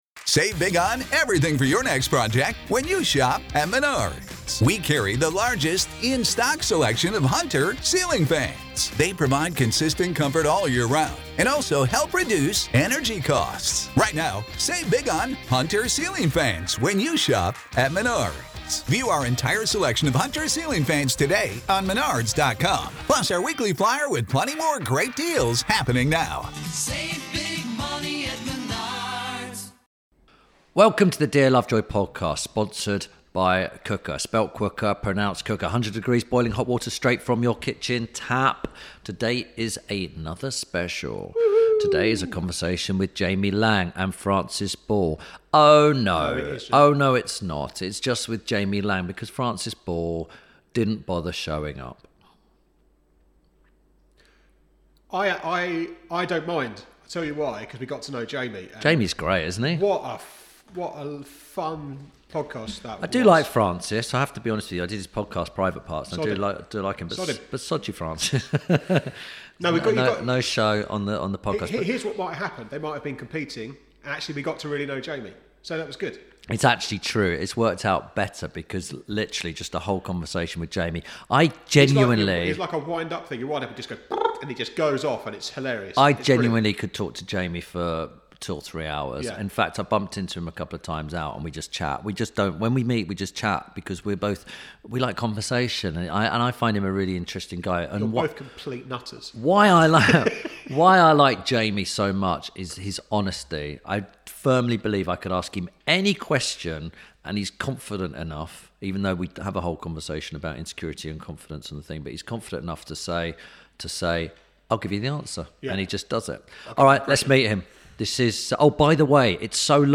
Ep. 147 – JAMIE LAING - A Conversation With… (PART 1) – INTERVIEW SPECIAL